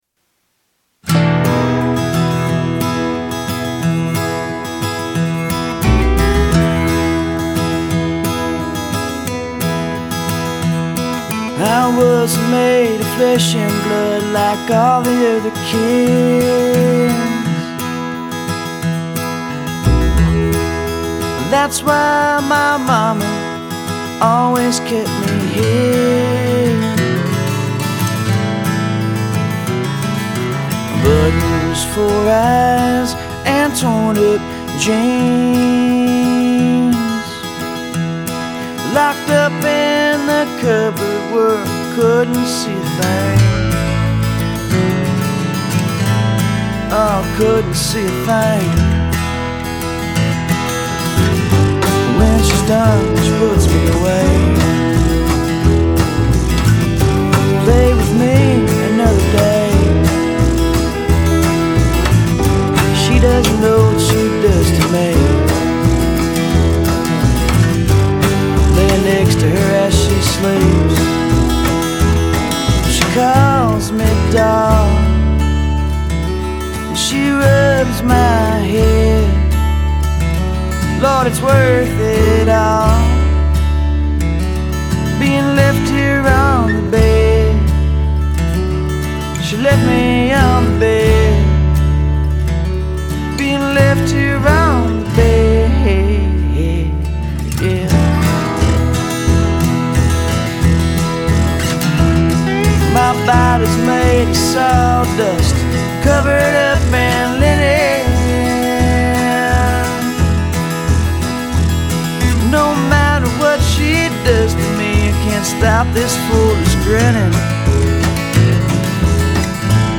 Hillbilly music!!
Upright bass is tough for me.